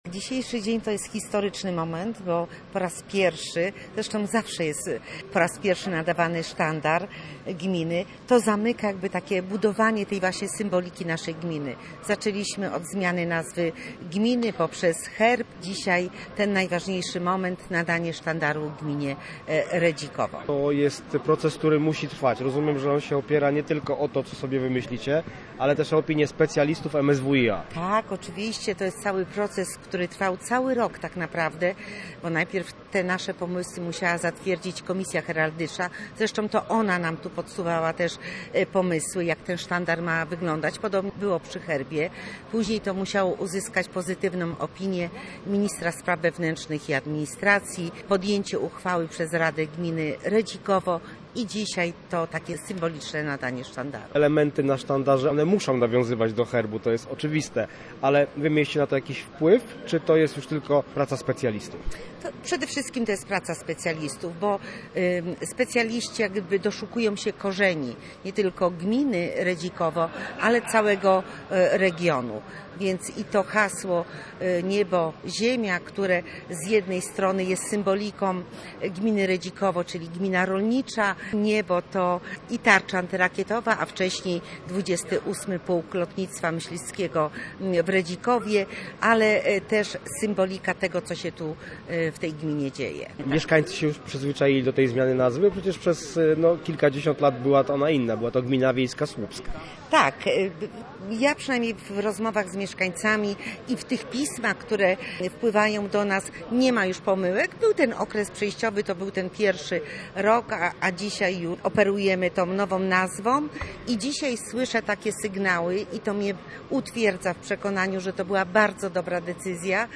Uroczystość odbyła się w hali sportowej w Jezierzycach, a jej kulminacyjnym momentem było premierowe wykonanie hymnu gminy. Posłuchaj relacji reportera: https